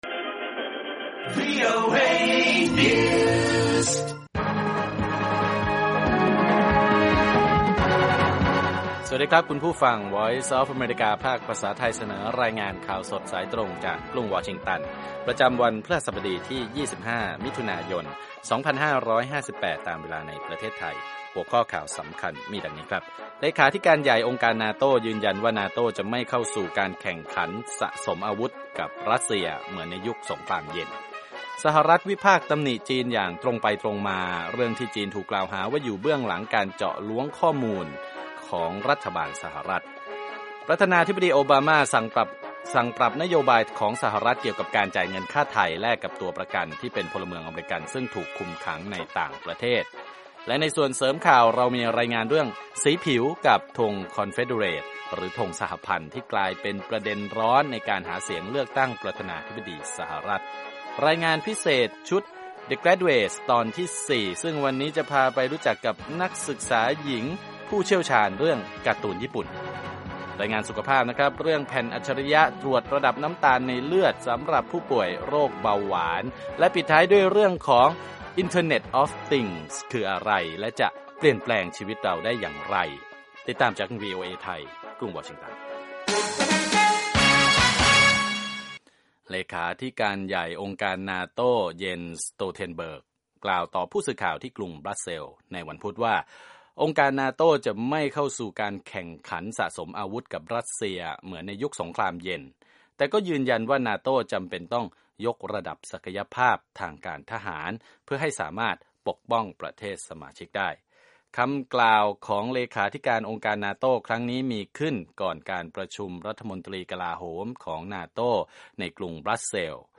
ข่าวสดสายตรงจากวีโอเอ ภาคภาษาไทย 6:30 – 7:00 น. พฤหัสบดีที่ 25 มิ.ย 2558